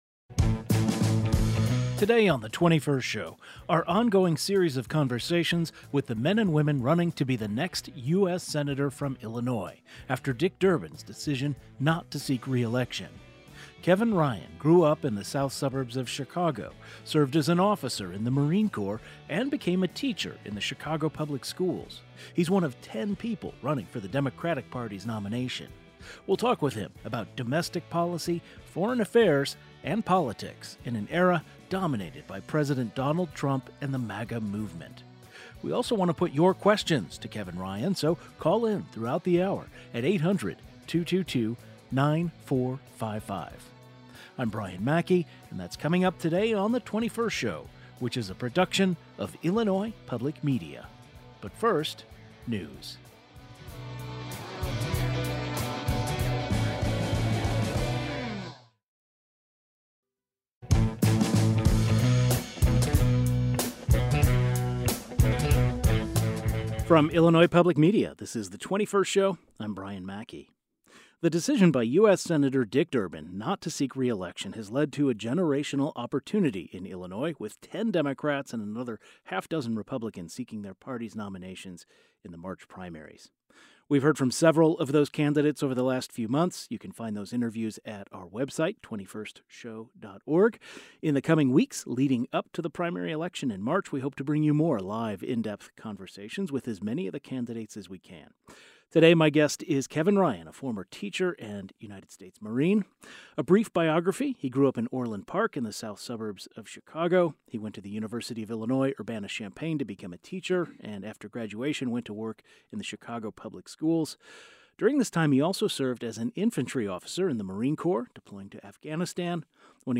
The decision by U.S. Senator Dick Durbin not to seek re-election has led to a generational opportunity in Illinois with ten Democrats and another half dozen Republicans seeking their parties’ nominations in the March primaries. In the coming weeks we hope to bring you more live, in-depth conversation with as many of the candidates as possible.